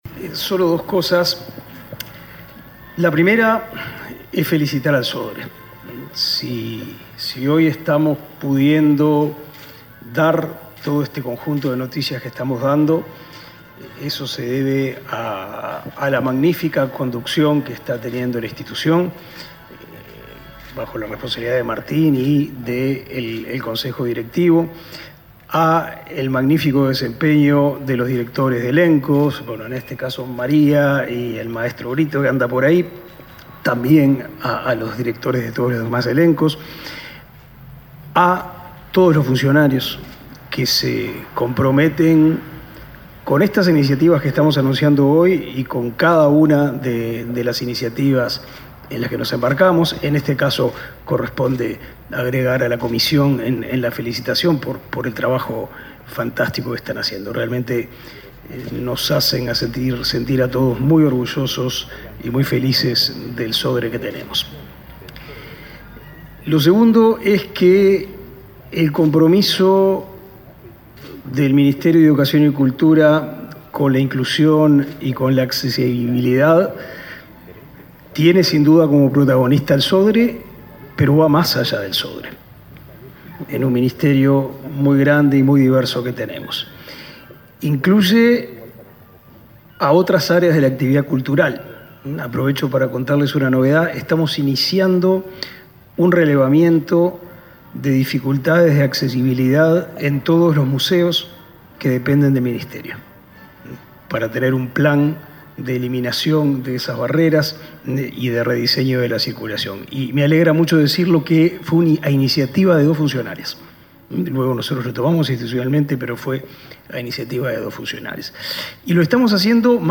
Palabras de los ministros de Educación y Cultura y Desarrollo Social
El ministro de Educación y Cultura, Pablo da Silveira; y su par de Desarrollo Social, Martín Lema, participaron este miércoles 1.°, de la presentación